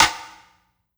Medicated Snare 35.wav